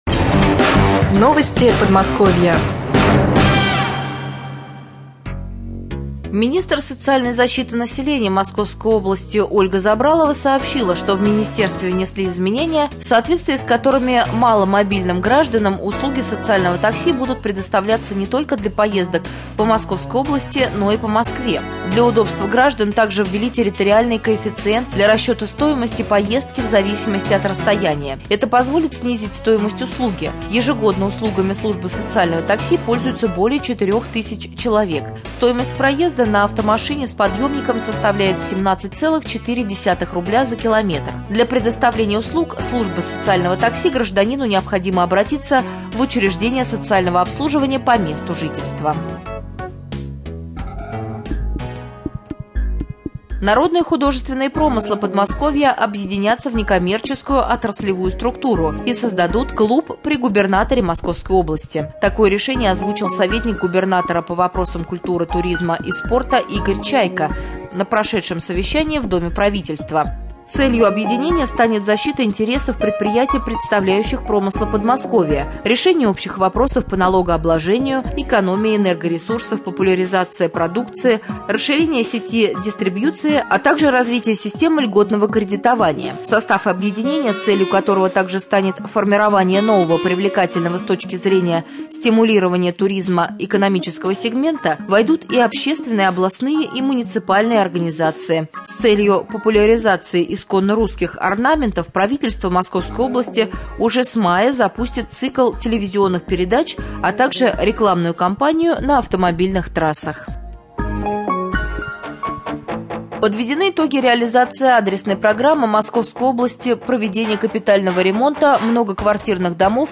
09.04.2014г. в эфире раменского радио